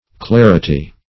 Clarity \Clar"i*ty\, n. [L. claritas, fr. clarus clear: cf. F.